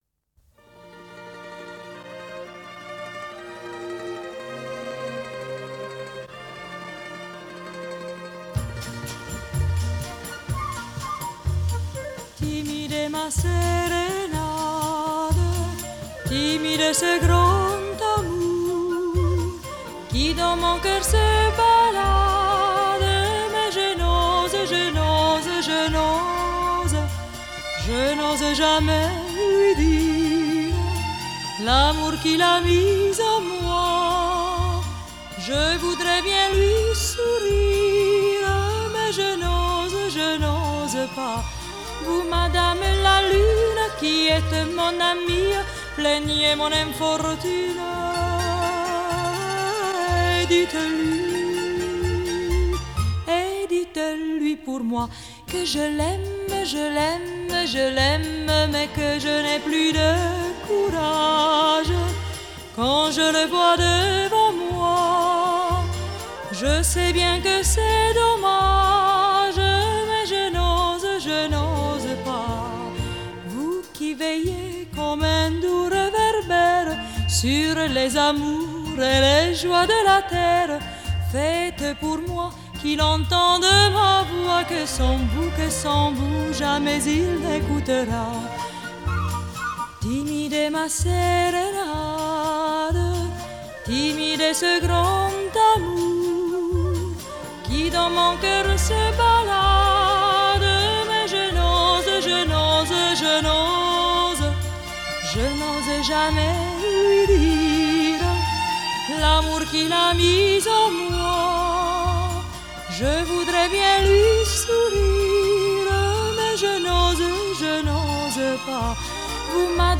Genre: Variete Francaise, Pop Vocale